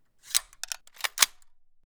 Added weapon resting & weapon jam sounds
fixing_rifle.wav